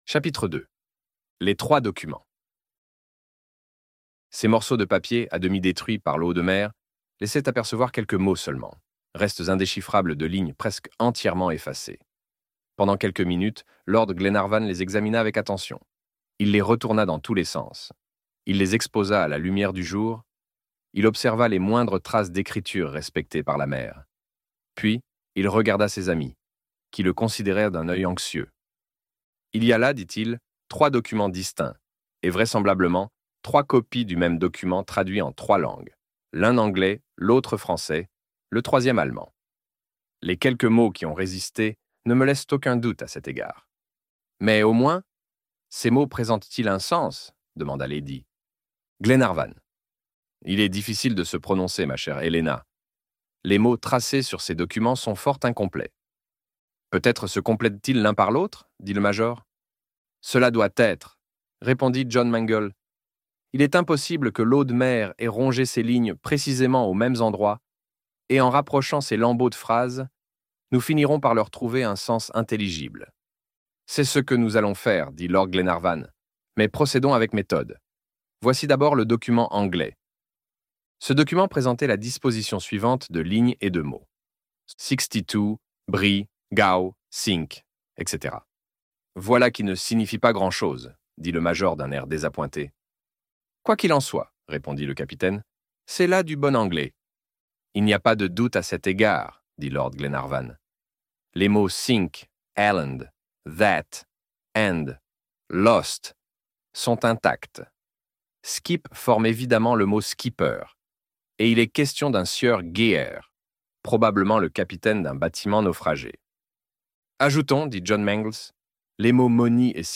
Les Enfants du capitaine Grant - Livre Audio